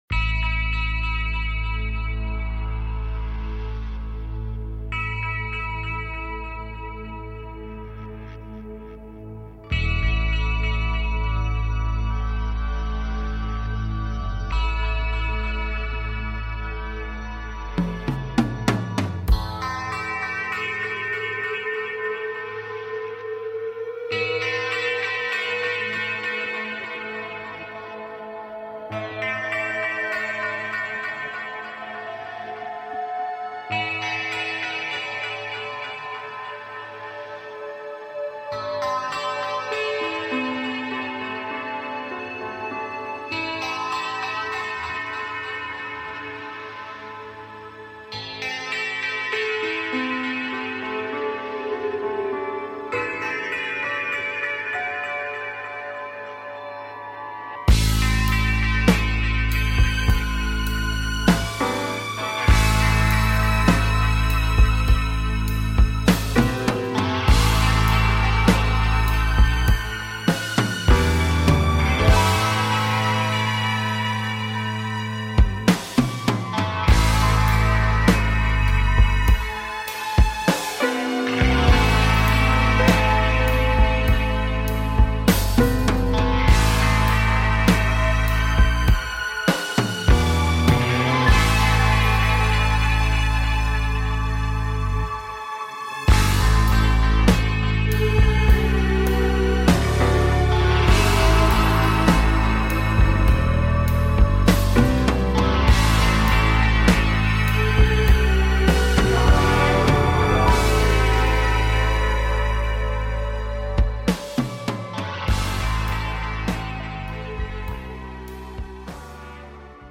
A show based on Timelines and manifesting Timelines while taking caller questions.